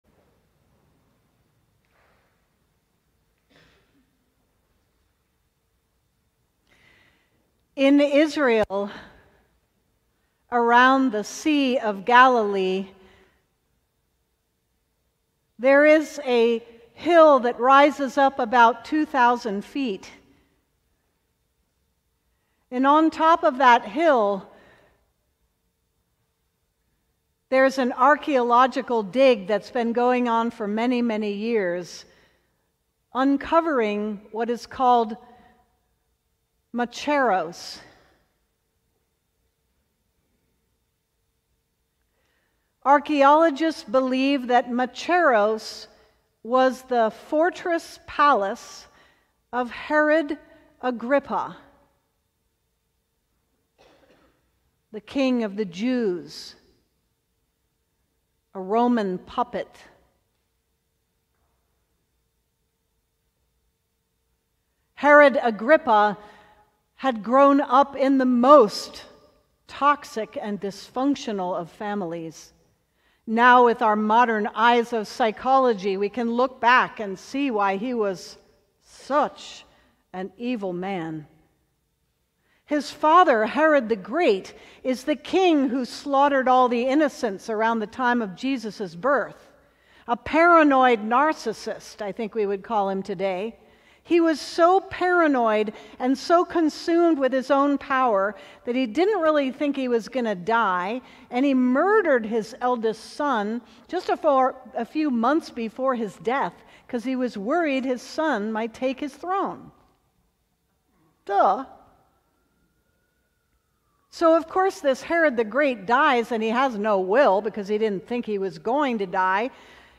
Sermon: Conquering Despair - St. John's Cathedral